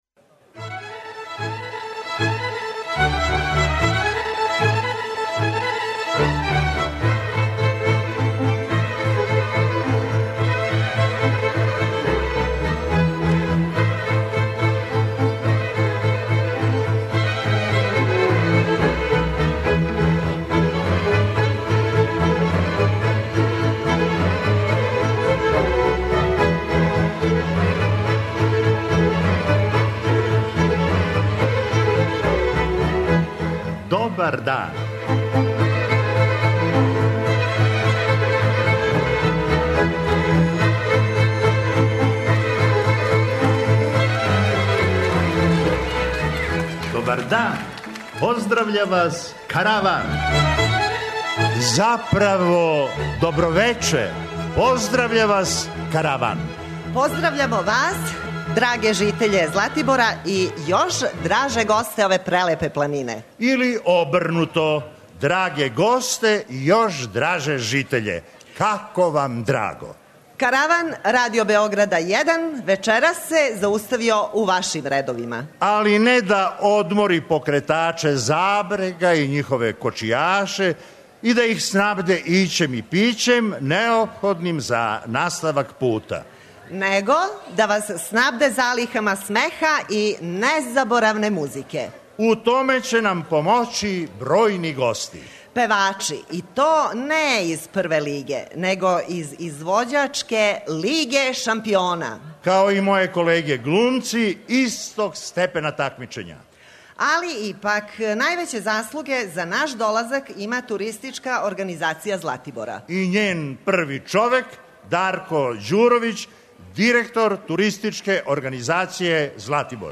Овога пута житељи Златибора имали су прилику да буду део јединственог и непоновљивог догађаја - присуствовали су јавном снимању Каравана, а ви сте данас у прилици да чујете управо тај златиборски Караван!
преузми : 24.19 MB Караван Autor: Забавна редакција Радио Бeограда 1 Караван се креће ка својој дестинацији већ више од 50 година, увек добро натоварен актуелним хумором и изворним народним песмама.